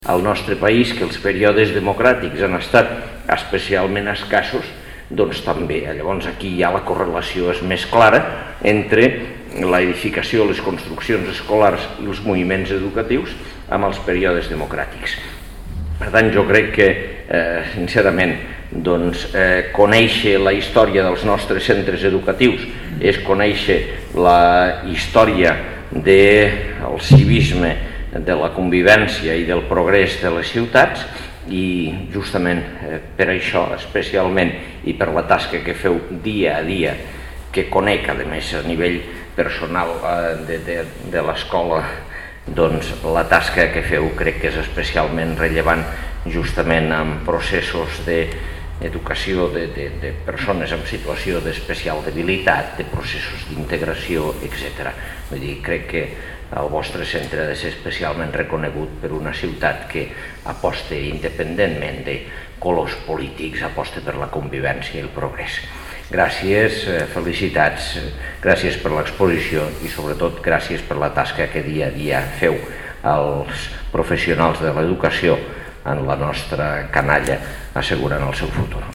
Tall de veu de l'alcalde de Lleida, Àngel Ros, sobre l'exposició del 80 aniversari de la construcció de l'edifici de l'Escola Pràctiques I de Lleida (1.2 MB) Fotografia 1 amb major resolució (3.0 MB) Fotografia 2 amb major resolució (3.1 MB) Fotografia 3 amb major resolució (2.3 MB) Fotografia 4 amb major resolució (2.1 MB)